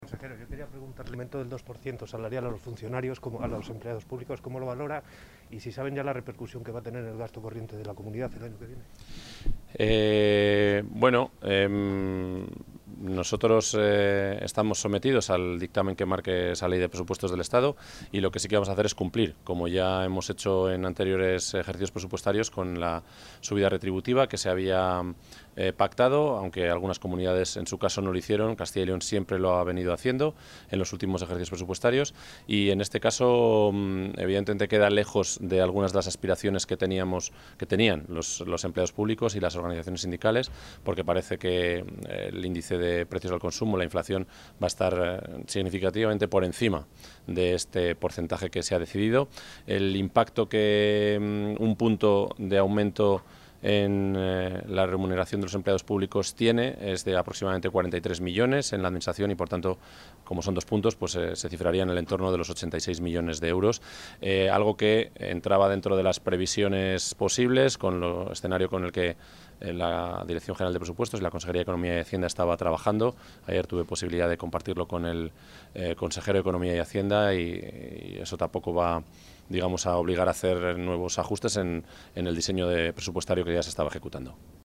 Declaraciones del consejero de la Presidencia.